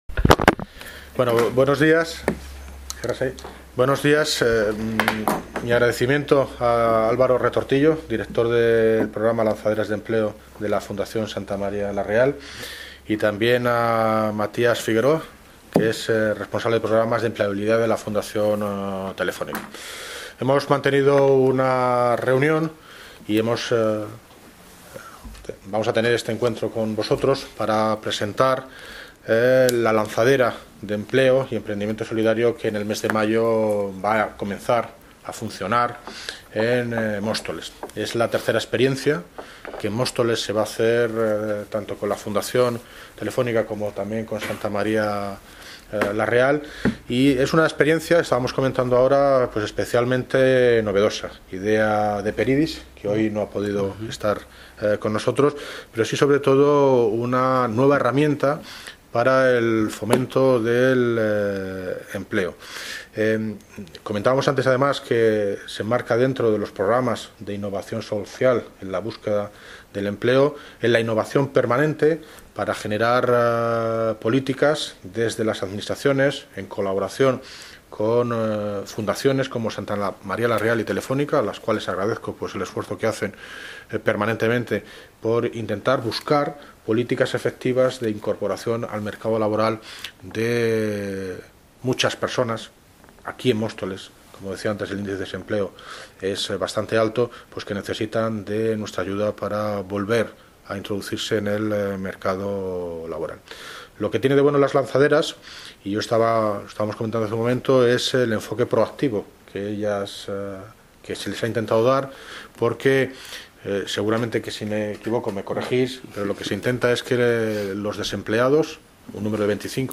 Audio - David Lucas (Alcalde de Móstoles) Sobre Convenio Lanzaderas de empleo